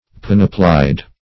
Panoplied \Pan"o*plied\, a.